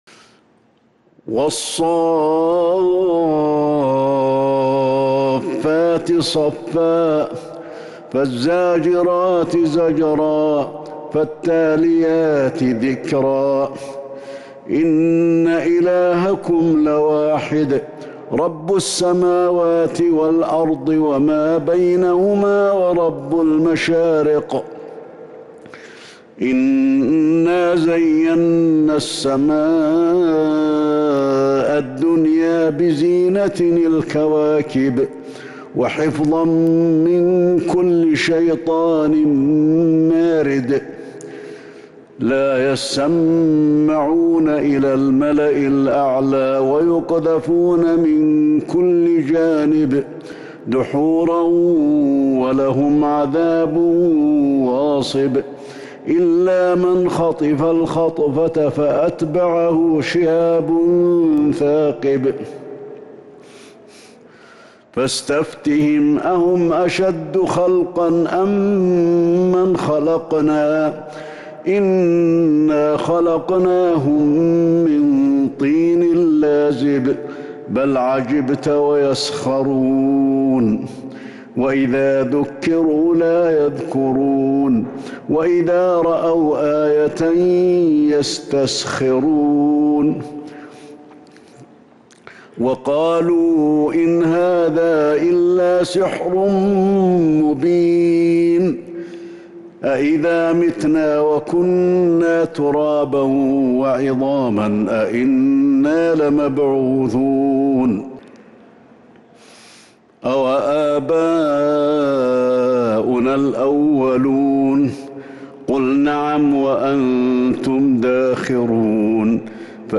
سورة الصافات كاملة من تراويح الحرم النبوي 1442هـ > مصحف تراويح الحرم النبوي عام 1442هـ > المصحف - تلاوات الحرمين